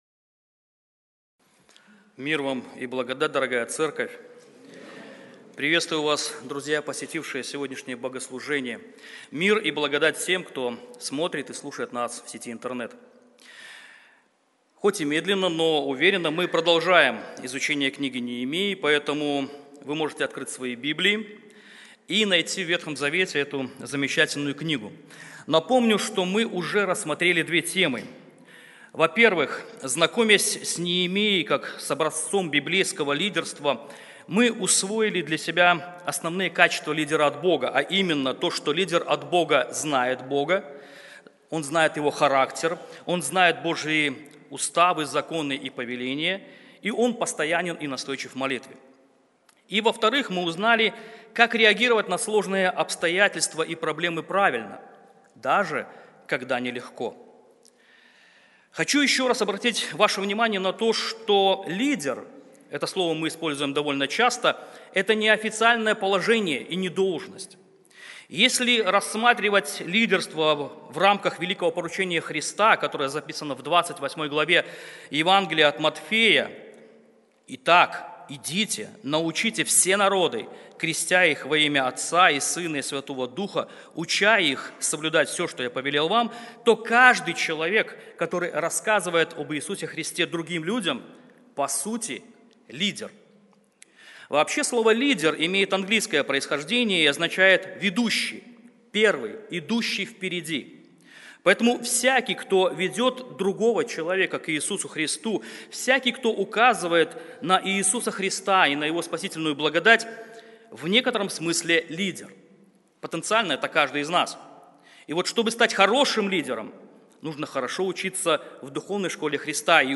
Серия проповедей по книге Неемии. Когда все против тебя, когда есть противники, когда ты устал и разочарован, когда твои духовные стены разрушены, когда все очень плохо – не отчаивайся!